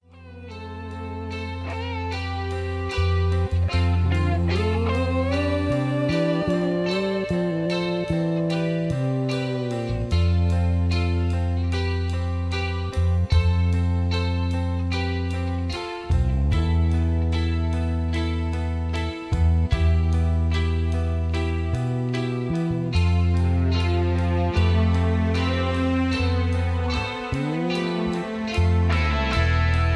karaoke, studio tracks, sound tracks, backing tracks, rock